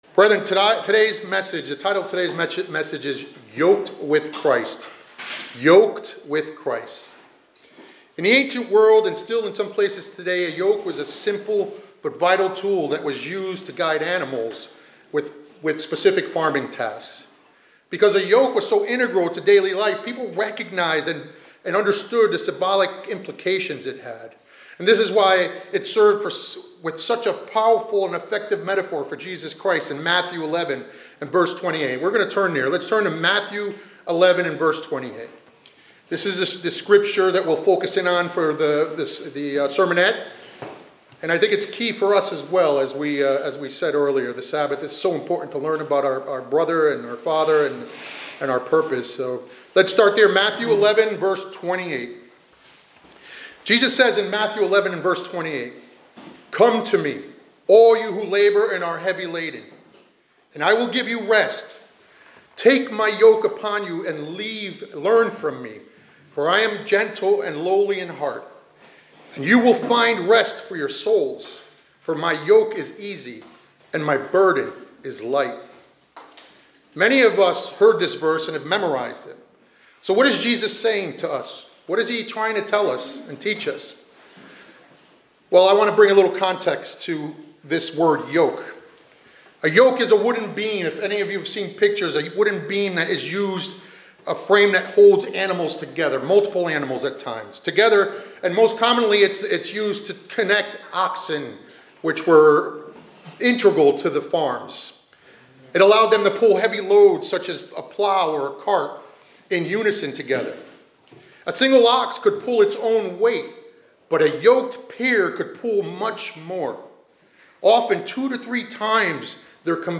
This sermonette explores the metaphor of being "Yoked with Christ " in Matthew 11:28-30, emphasizing a spiritual partnership that brings rest and strength amidst life's burdens. It draws a comparison between the traditional farm yoke and the spiritual yoke offered by Jesus Christ.